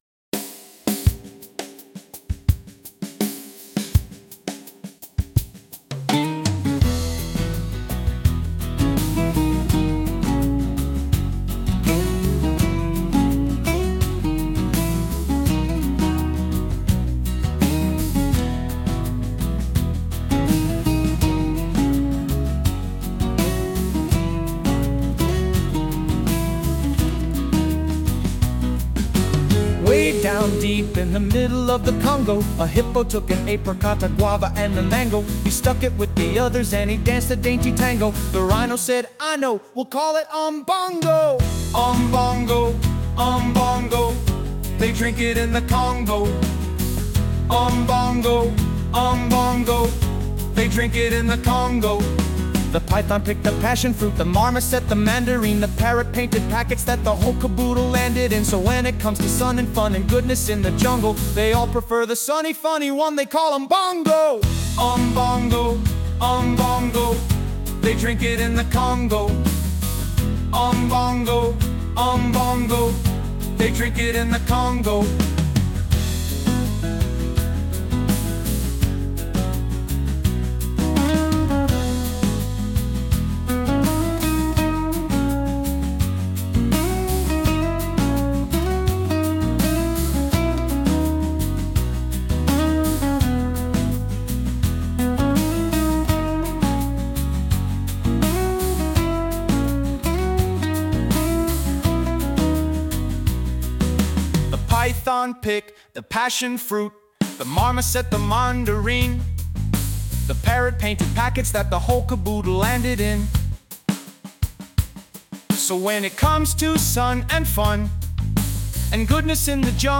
7. 70s chart topping hit